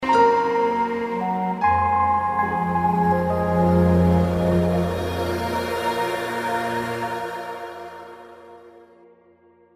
هشدار پیامک